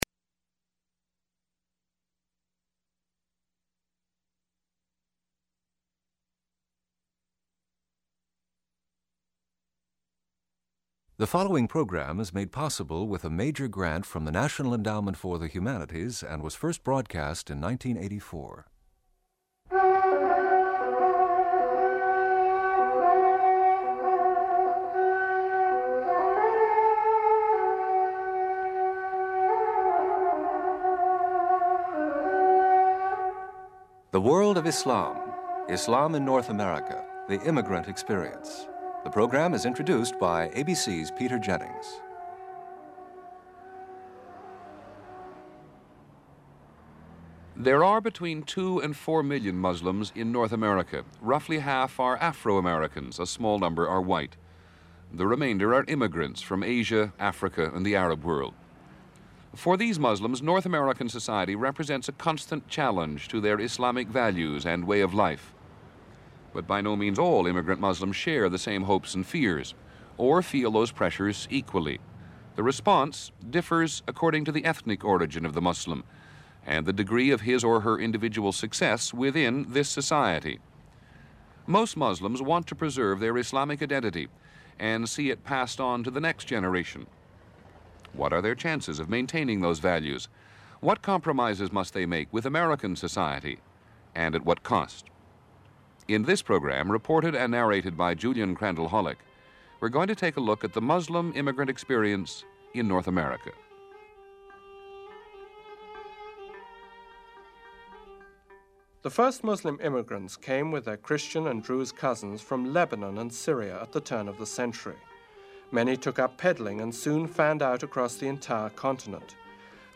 People interviewed in this program